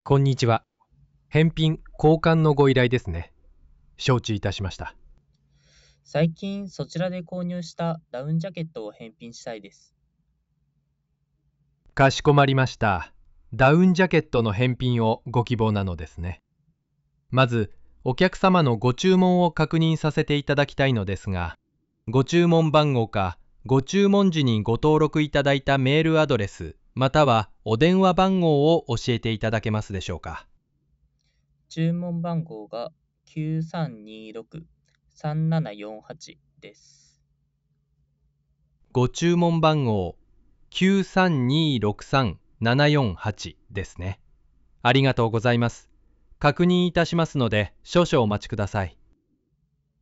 ボイス設定の変更と、言語設定をデフォルトの英語から日本語にしました。
今回は実際に「ダウンジャケットを買ったけど、サイズが合わなかった」というロープレで、AIエージェントと会話してみました。
実際に話してみた感覚としては、数分で作成したとは思えないほど、想像以上に自然な会話が行えました。